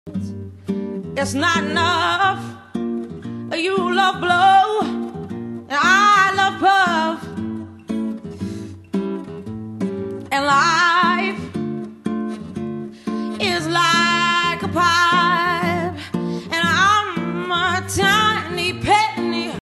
(Live Acoustic)
guitarist